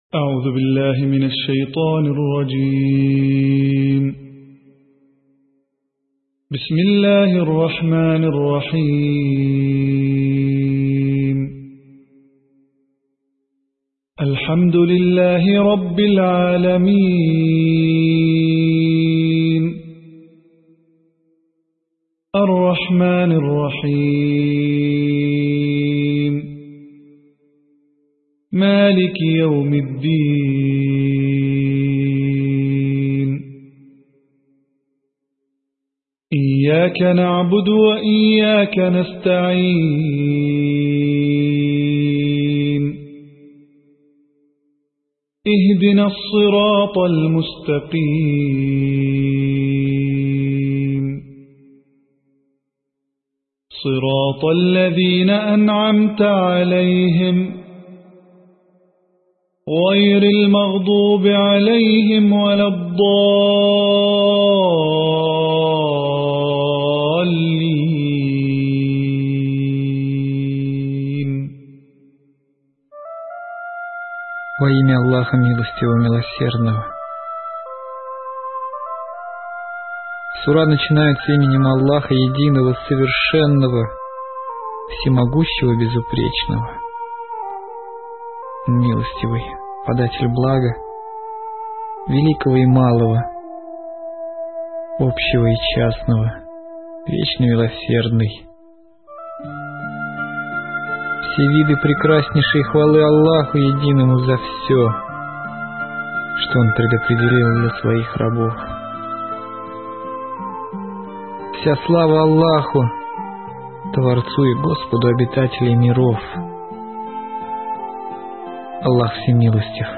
Звук намаза в мечети: сура Аль-Фатиха